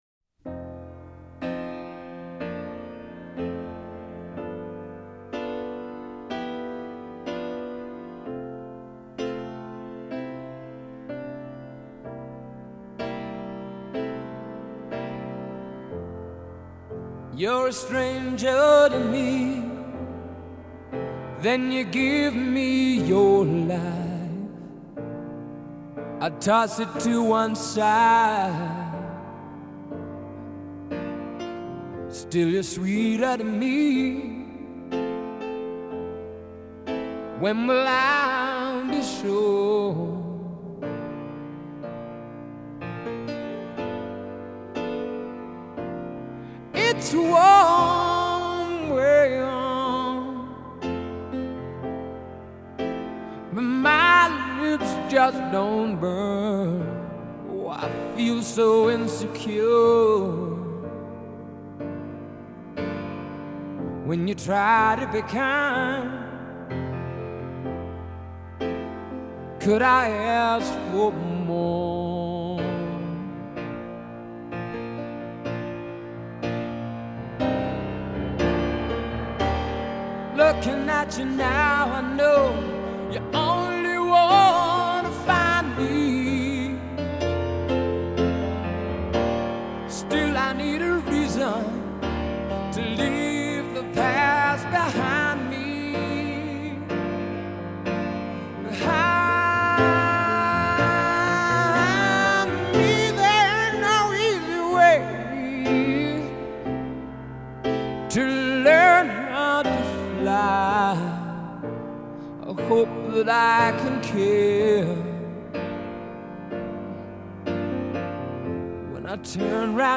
a lovely ballad